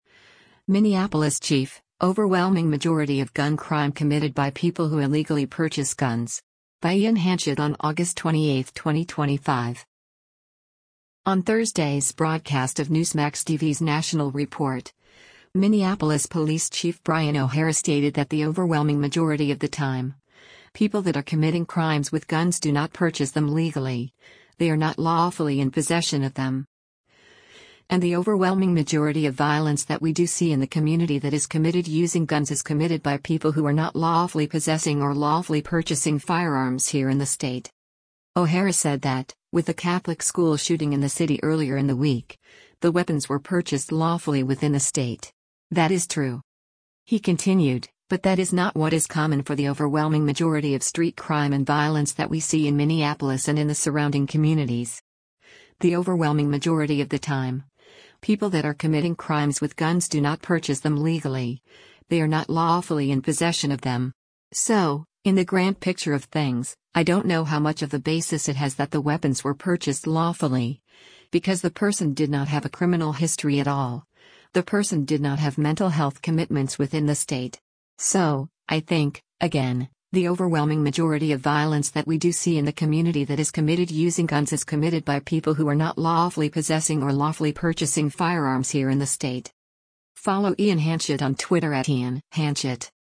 On Thursday’s broadcast of Newsmax TV’s “National Report,” Minneapolis Police Chief Brian O’Hara stated that “The overwhelming majority of the time, people that are committing crimes with guns do not purchase them legally, they are not lawfully in possession of them.” And “the overwhelming majority of violence that we do see in the community that is committed using guns is committed by people who are not lawfully possessing or lawfully purchasing firearms here in the state.”